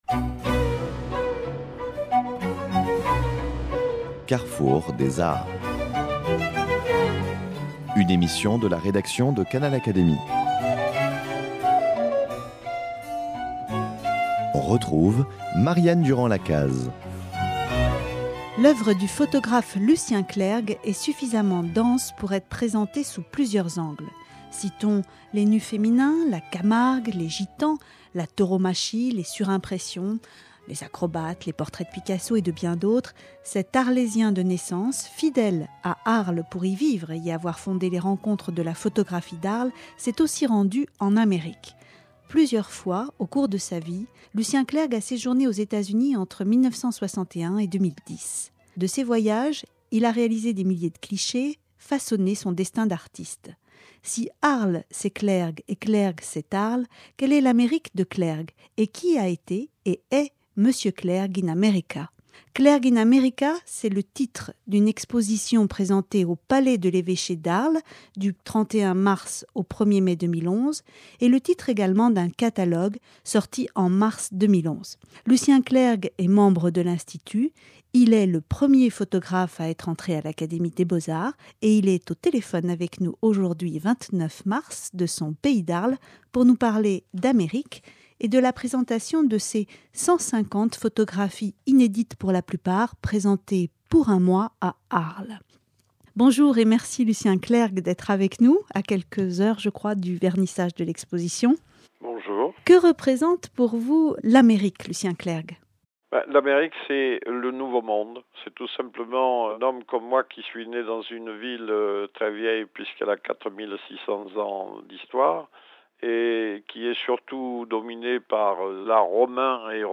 L’exposition au Palais de l’Archêché à Arles, du jeudi 31 mars au dimanche 1er mai, présente 150 photographies du premier photographe à être entré à l’Institut, au sein de l’Académie des beaux-arts : entretien avec l’artiste Clergue in America.